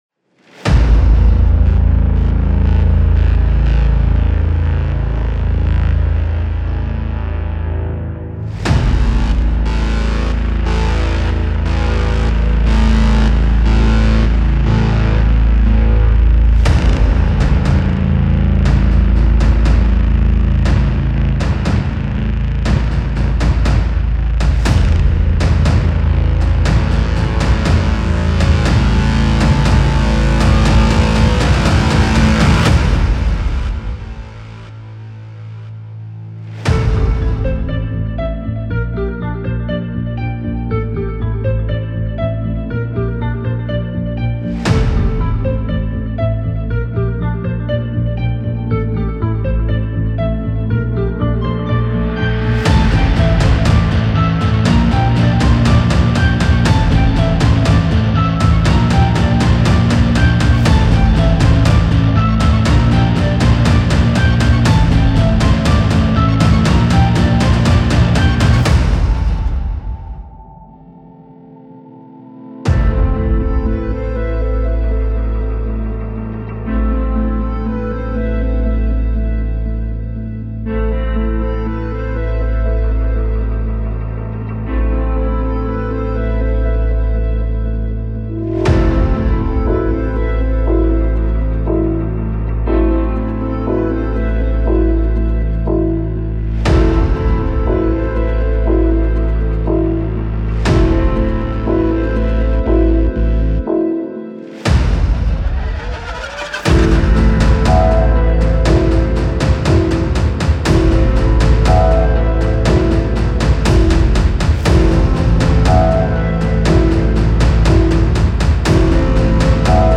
Dark Bass Virtual Instrument by Replika Sound
• 9 Larger-than-Life Braam-type Dirty Dark Basses.
• Harp-like Bass with note range of 6 Octaves.
• 5 different Sound Sets – Body Hits & Slams (Pitched & Unpitched), Tremelo Body Hits, Scrapes and Harmonic Note Hits.
rssd03_dark_bass_demo.mp3